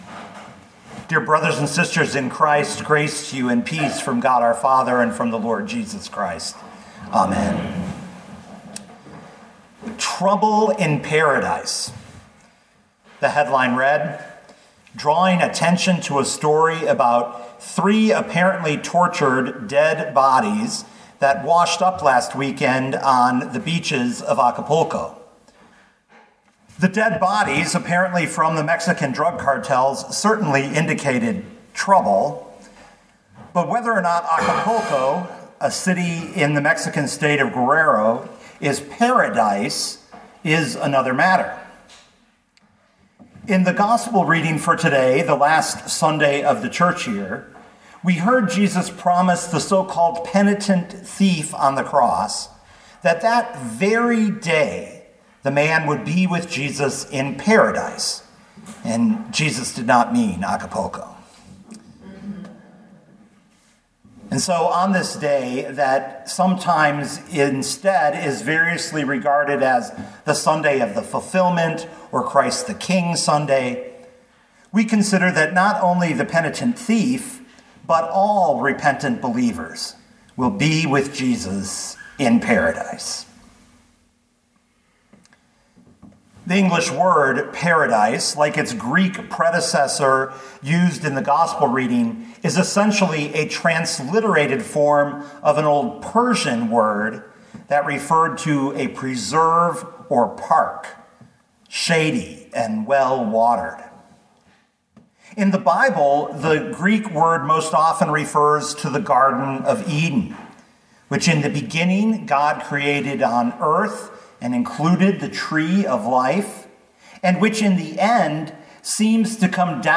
2022 Luke 23:27-43 Listen to the sermon with the player below, or, download the audio.